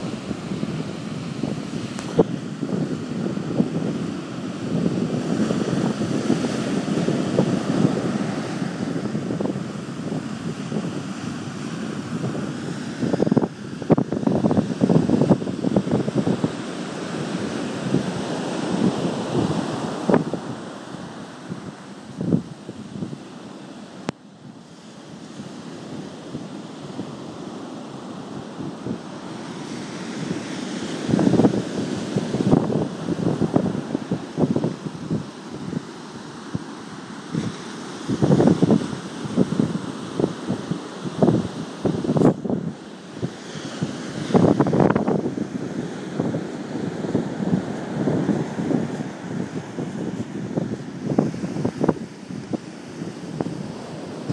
A walk on the beach